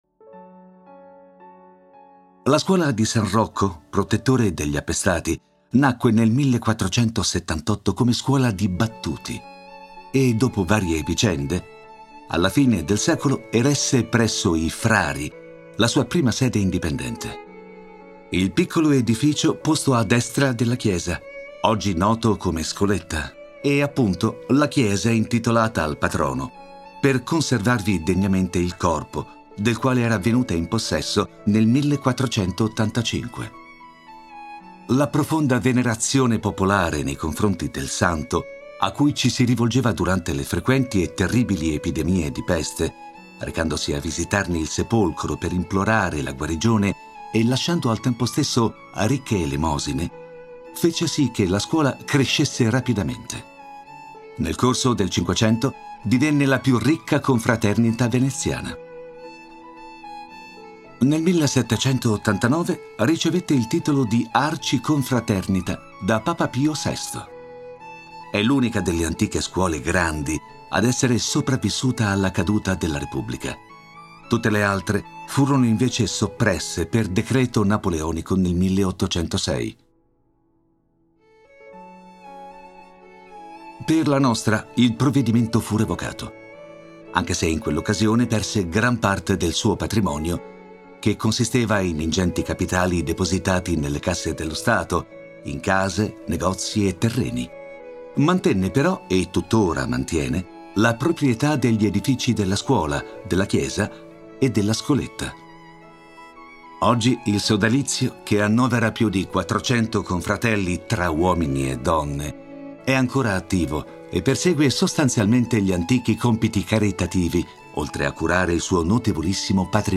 Le nostre tracce audio sono prodotte da professionisti del settore cinematografico: attori madrelingua e compositori professionisti danno vita a una colonna sonora originale per raccontare il tuo museo.